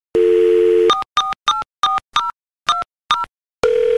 Dial-Tone-Dialing-Old-Phone-Ringing-Sound-Effect-Free-High-Quality-Sound-FX.mp3